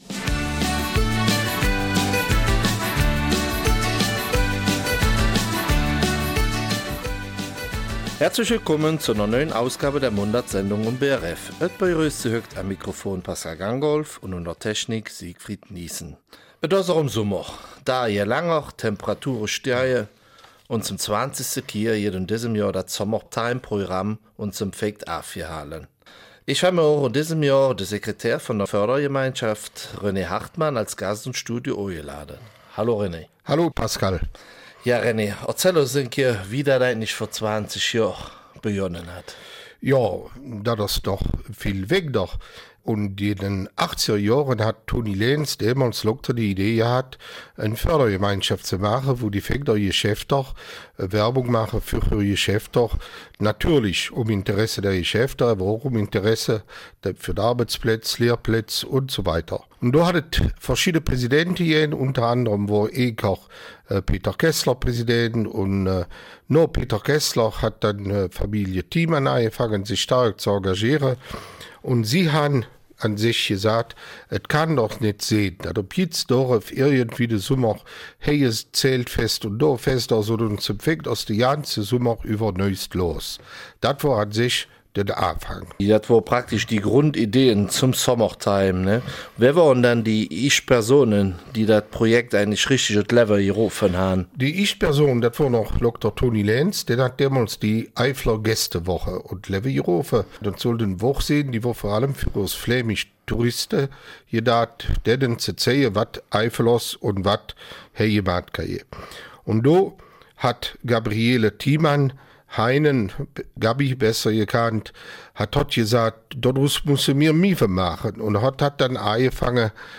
Eifeler Mundart: Summertime 2017 in St. Vith